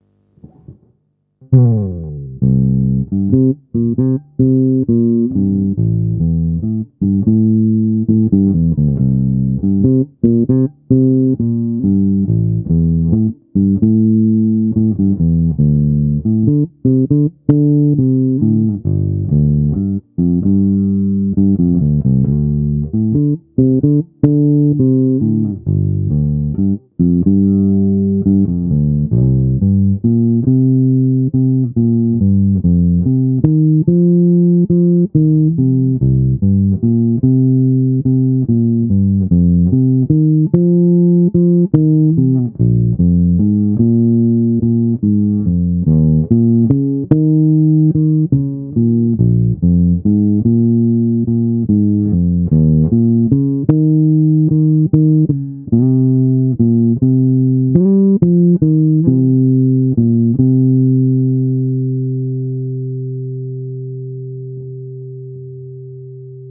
Nahrávky s hlazenkama D´addario chromes ECB81:
Hlazenky tonovka stažená